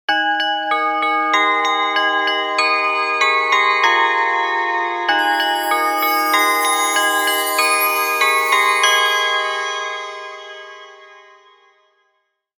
Elegant Festive Chime Logo
A soothing logo or ident that brings holiday warmth to your project, perfect for adding festive spirit and charm.
Genres: Sound Logo
Elegant-festive-chime-logo.mp3